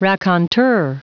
Prononciation du mot raconteur en anglais (fichier audio)
Prononciation du mot : raconteur